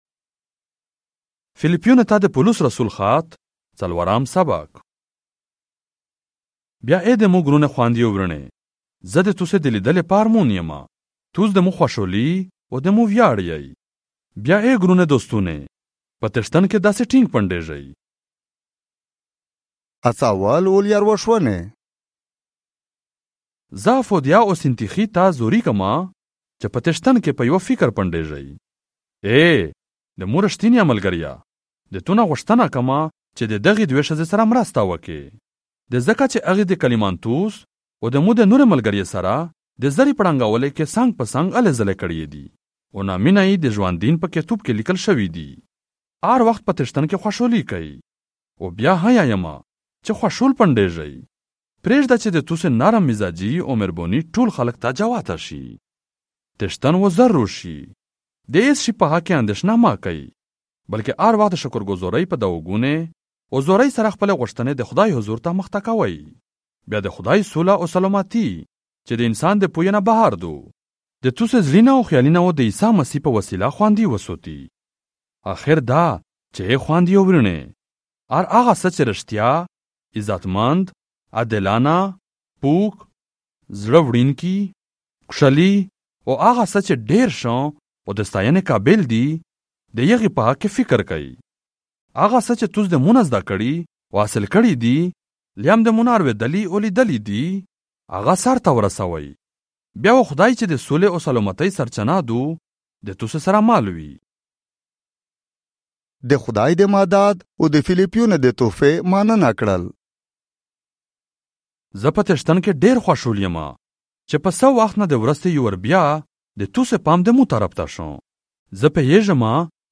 Philippians - Chapter 4 in the Pashto language, Central - audio 2025